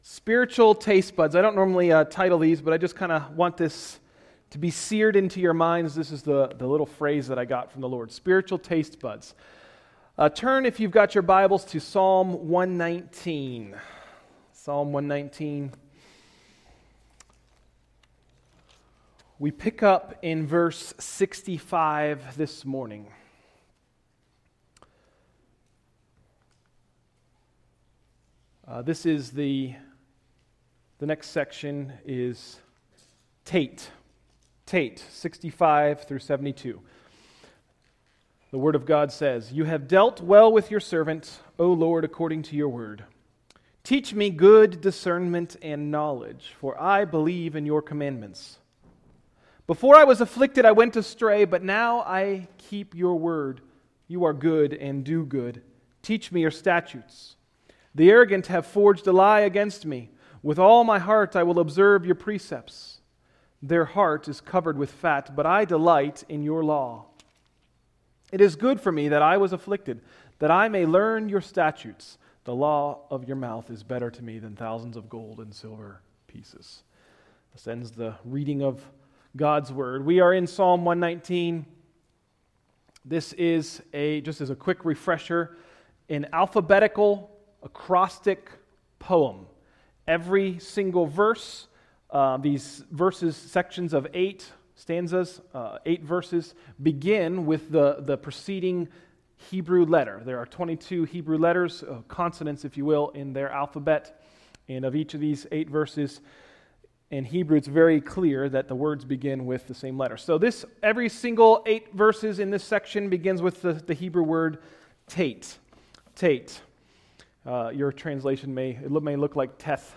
Sermons – Tried Stone Christian Center